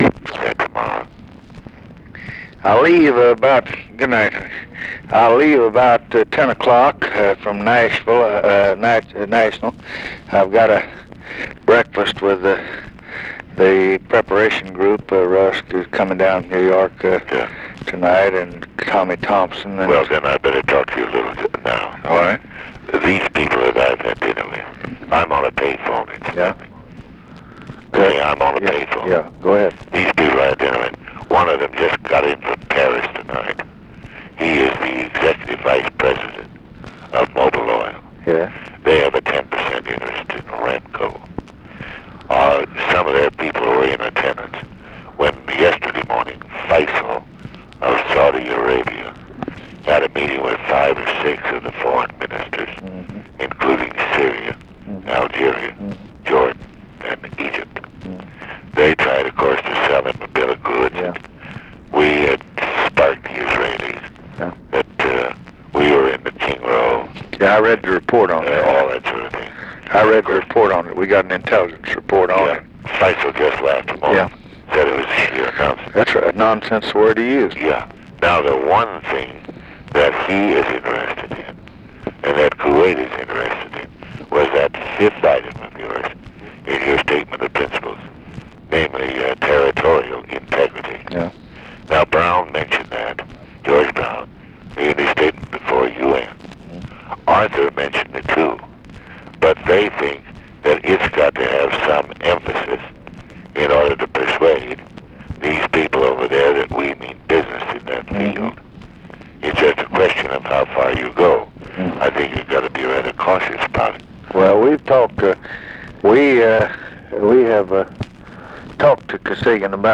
Conversation with EVERETT DIRKSEN, June 23, 1967
Secret White House Tapes